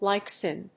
アクセントlìke sín